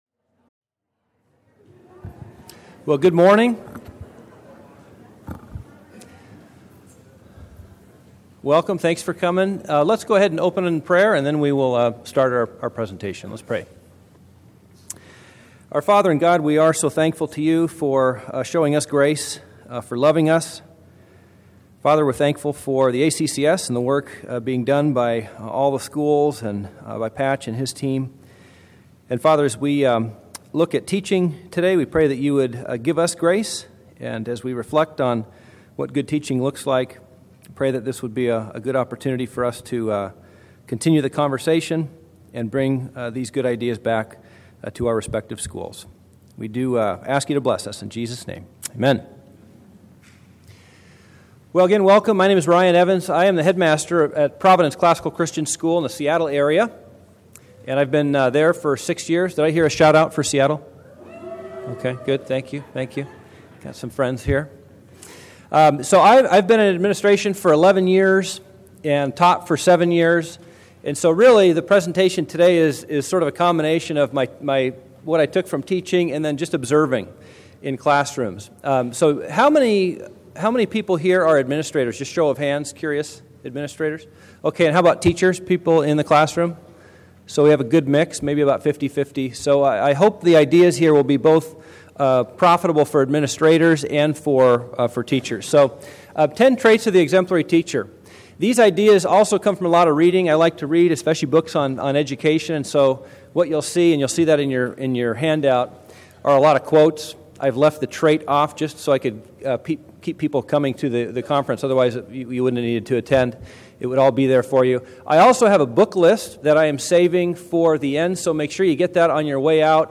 2012 Workshop Talk | 1:01:47 | All Grade Levels, General Classroom
Speaker Additional Materials The Association of Classical & Christian Schools presents Repairing the Ruins, the ACCS annual conference, copyright ACCS.